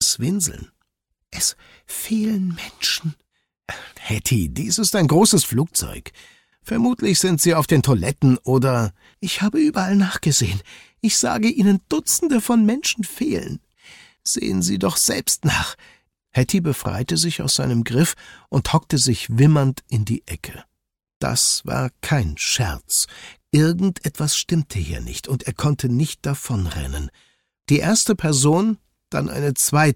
Finale - Hörbuch